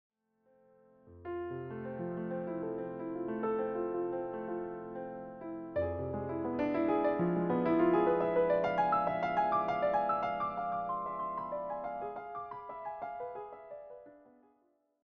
all arranged and performed as solo piano pieces.